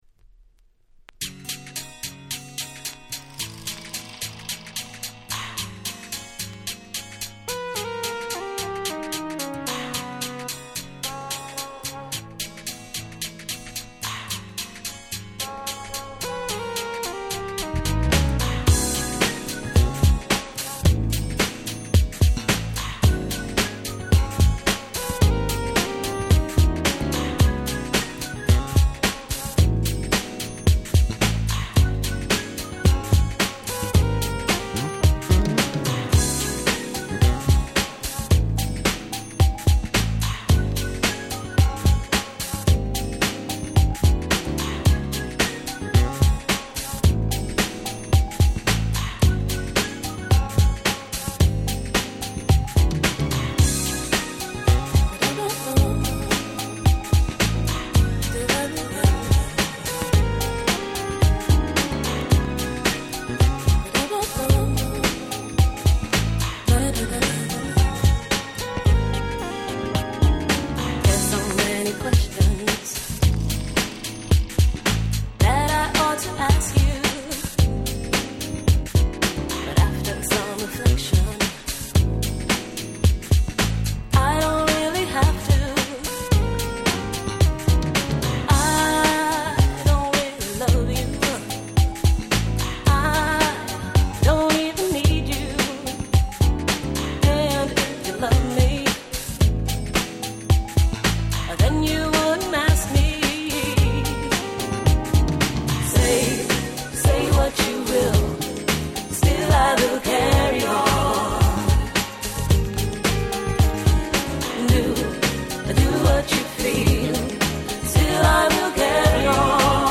決して派手さは無い物の、クリスタルピアノの美しい旋律が堪らないJazzyでGroovyな良曲。